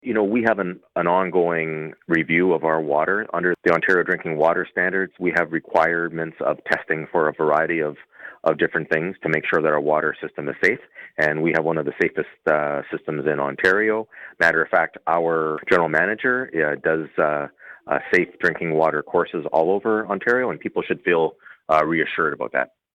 Mayor Panciuk is confident in the drinking water quality.